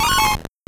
Cri de Farfuret dans Pokémon Or et Argent.